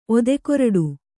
♪ odekorḍu